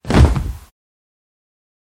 Звуки падения человека
Звук падающего тела